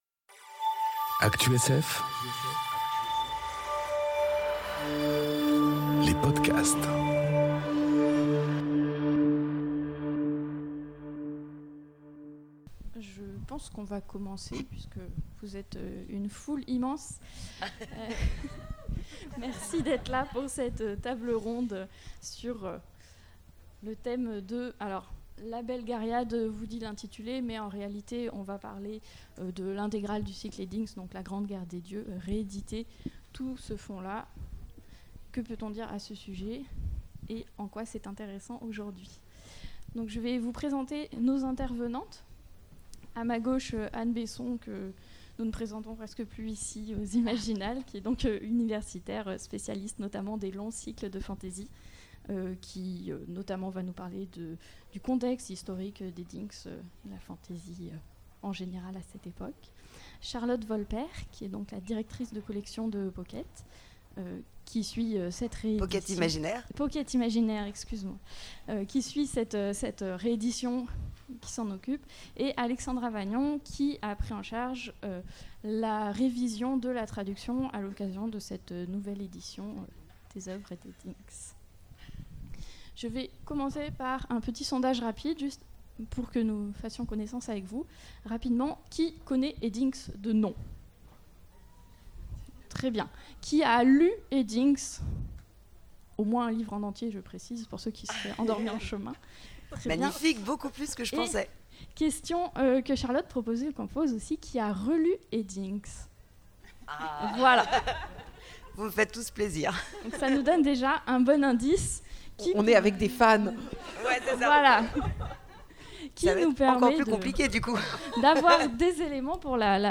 Imaginales 2021 - Rééditer une grande série de fantasy : « La Belgariade » de David Eddings
Modération